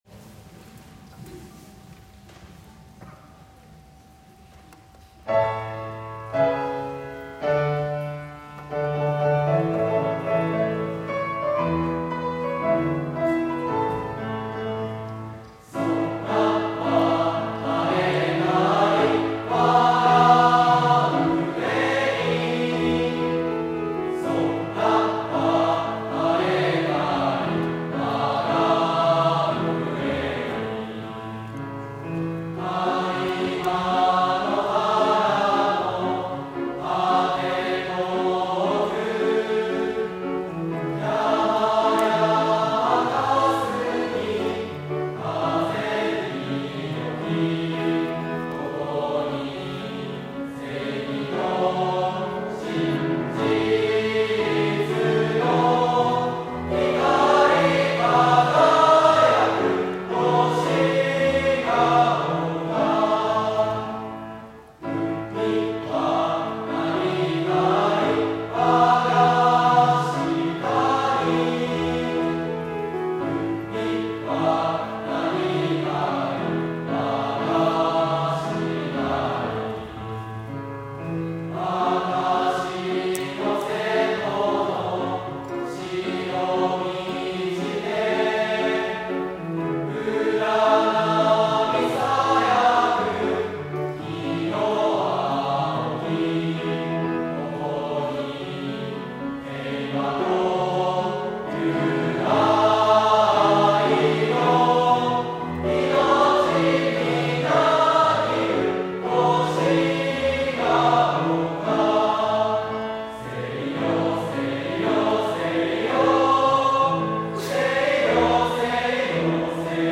当日、１年生の合唱コンクールが行われました。
その歌声はとても清らかで、心に響きました。
すべてのクラスの合唱を紹介することはできませんが、優勝した１年２組の校歌の合唱音源を載せます。
250523_校歌（１年２組）.m4a